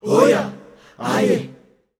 Index of /90_sSampleCDs/Zero G Creative Essentials Vol 27 Voices Of Africa WAV/Voices of Africa Samples/Track 05